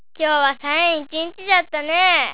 ためになる広島の方言辞典 さ．